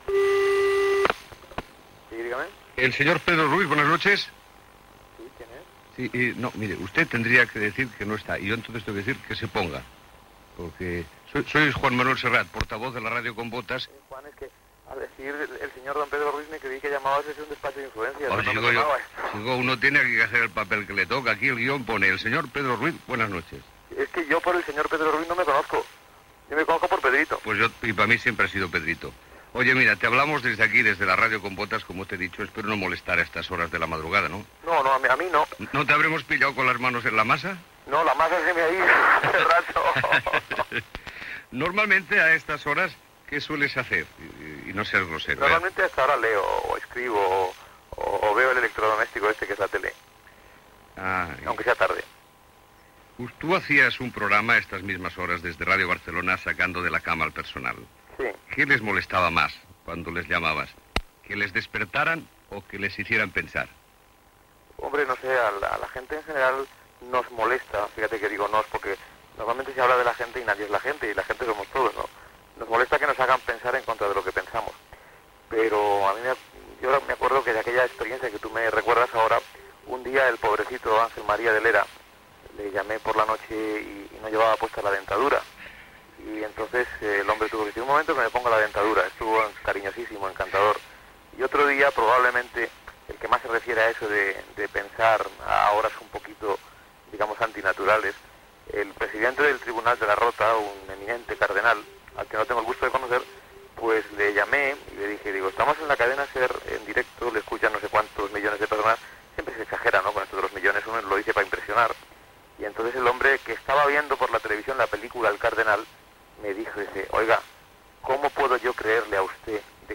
Entrevista a Pedro Ruiz que va presentar a la SER el programa "Que se ponga", l'any 1981